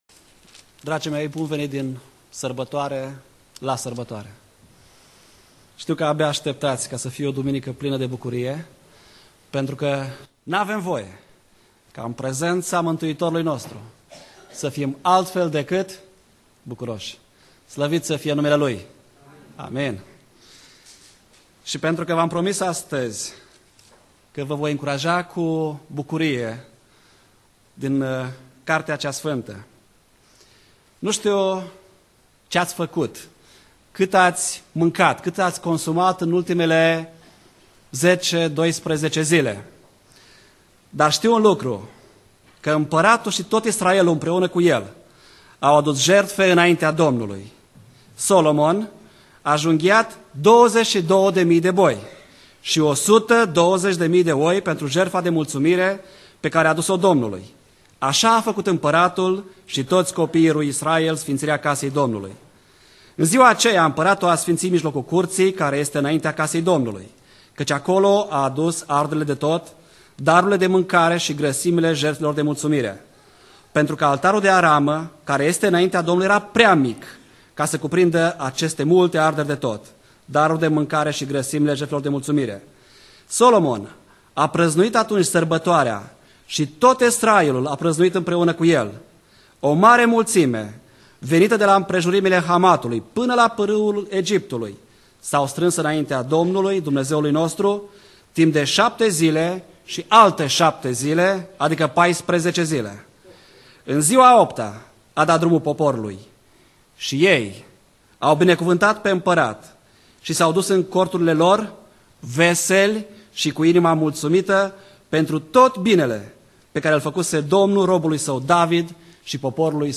Predica Exegeza - 1 Imparati Cap 8